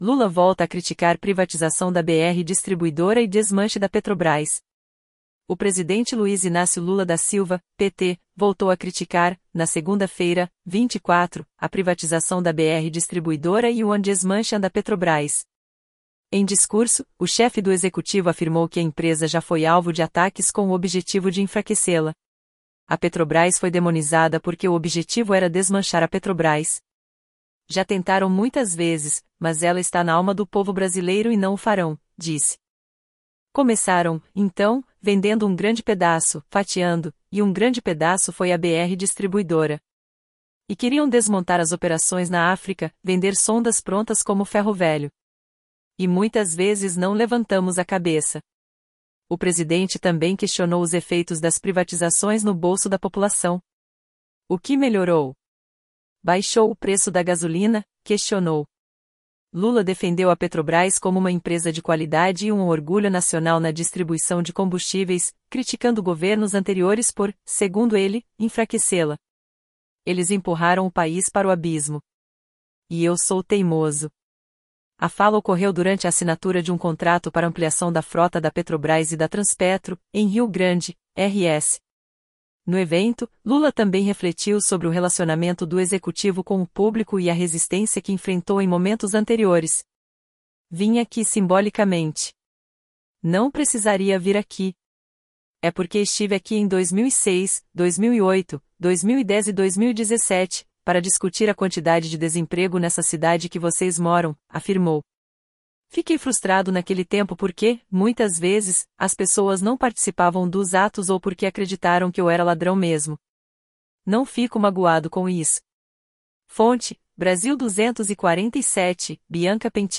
Em discurso, o chefe do Executivo afirmou que a empresa já foi alvo de ataques com o objetivo de enfraquecê-la.
A fala ocorreu durante a assinatura de um contrato para ampliação da frota da Petrobrás e da Transpetro, em Rio Grande (RS).